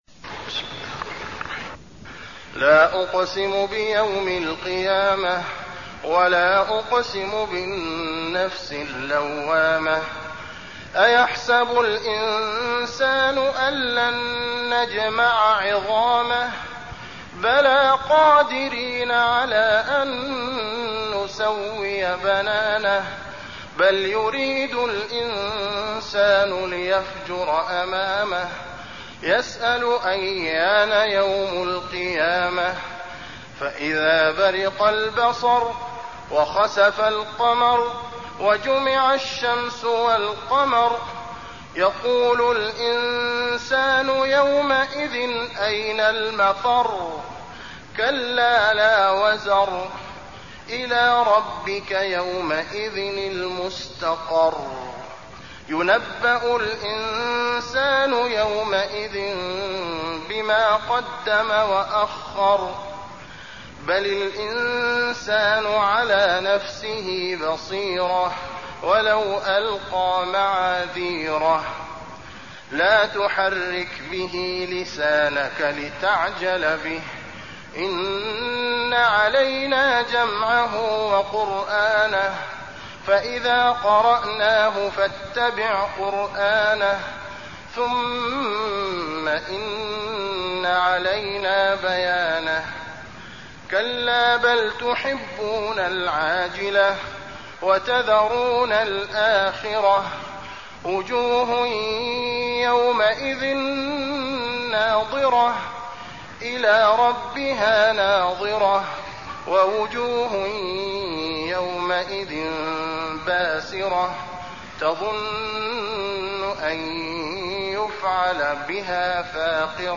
المكان: المسجد النبوي القيامة